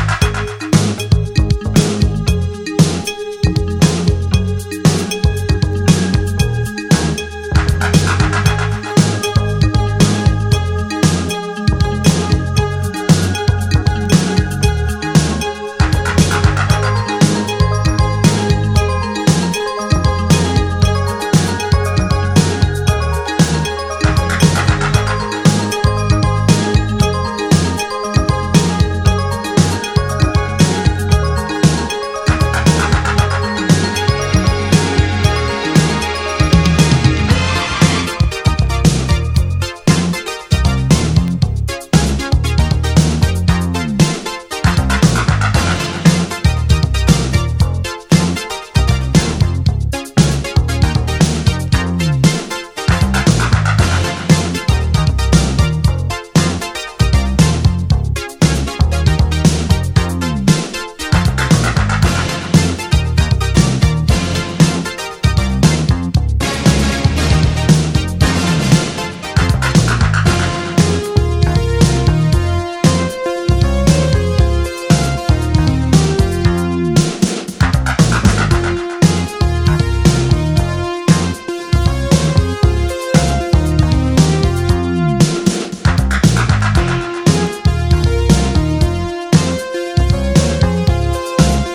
UK UNDERGROUND HIP HOP
メロウ且つドープなUK UNDERGROUND HIP HOP！
メロウな上モノとストレンジなプロダクションの取り合わせがドープな
カラフルでポップなサウンド・メイキングが光る